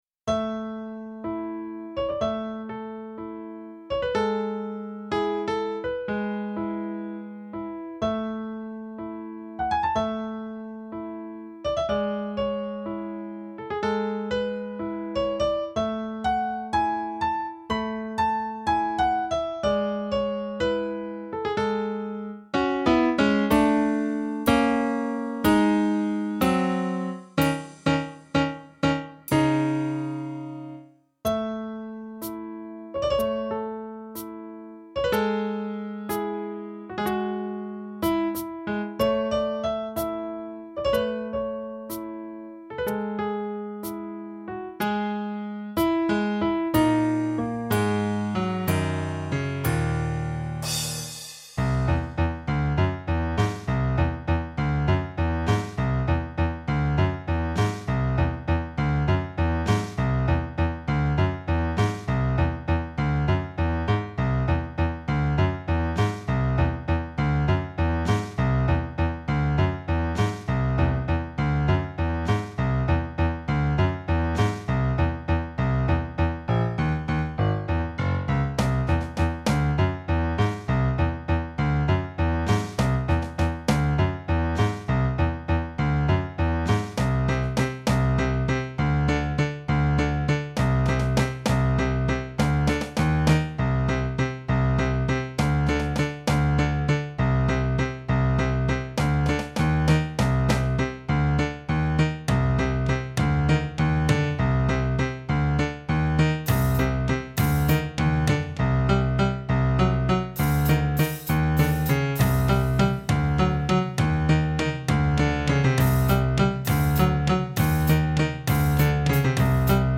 melodía
música